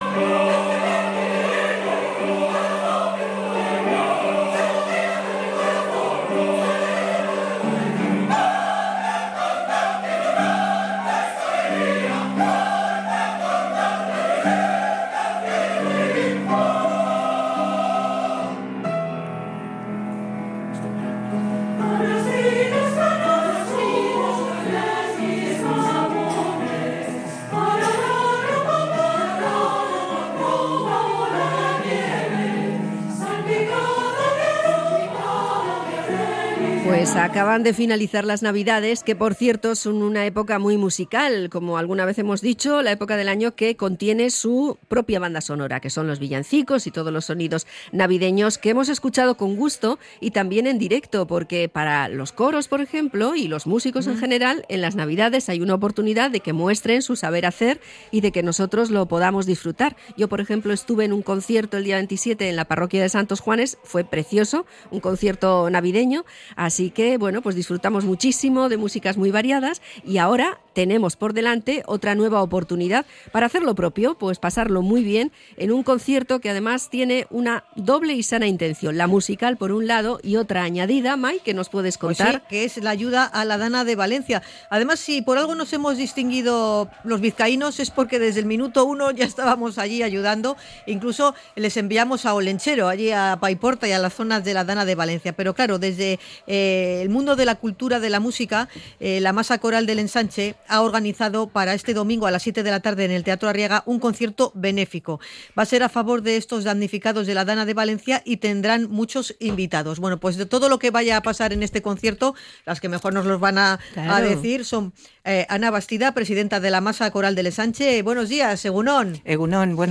MASA-CORAL-DEL-ENSANCHE-ENTREVISTA.mp3